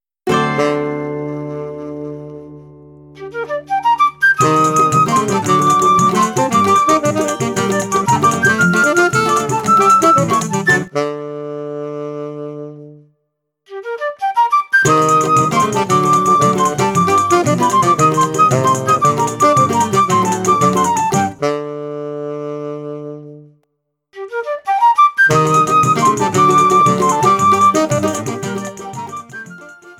– Full audio with choro ensemble, solo and counterpoint.
flute
tenor saxophone